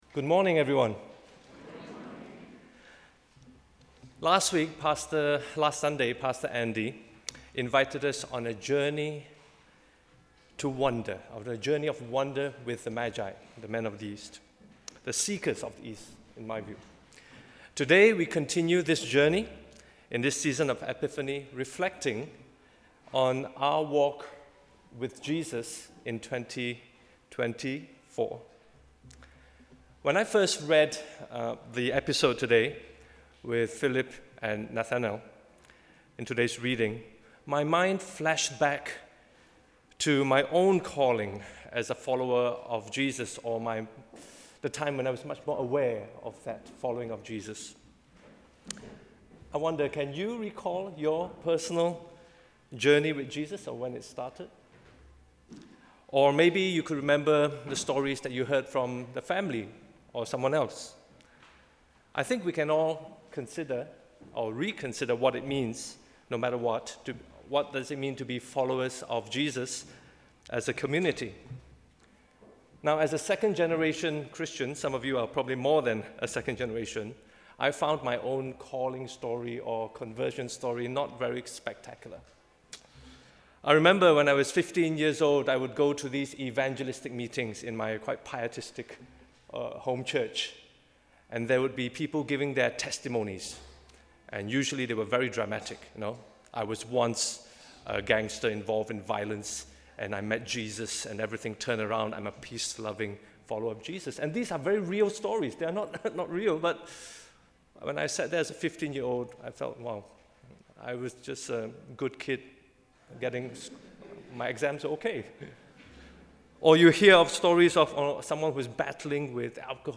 Sermon: ‘Seeing beyond the surface’
Sermon on the Second Sunday after Epiphany